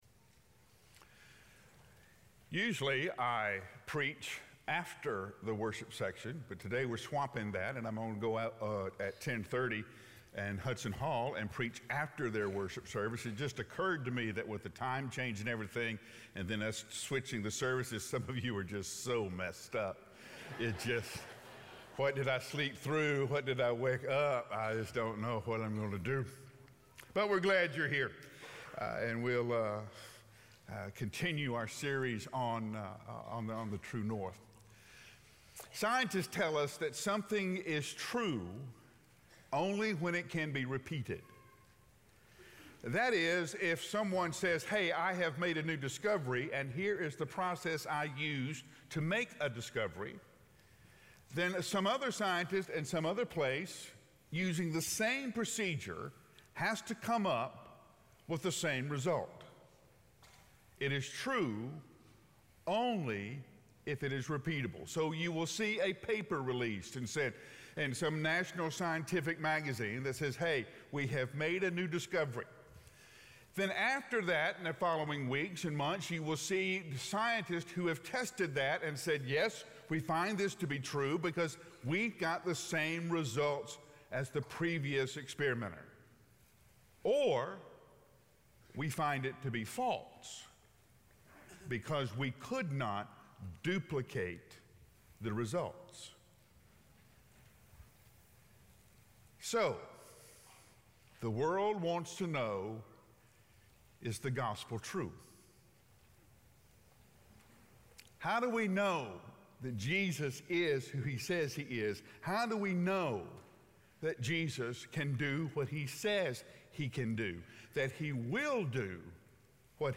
Finding Our Way with Racial Reconciliation - Sermon - Brentwood Baptist